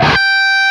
LEAD G 4 LP.wav